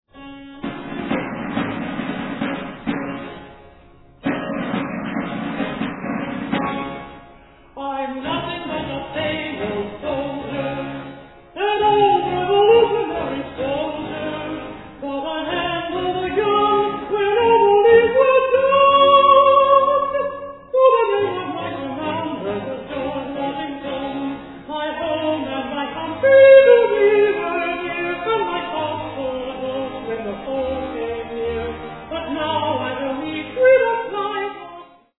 countertenor
harpsichordist
on period instruments
on authentic Civil War-era military drums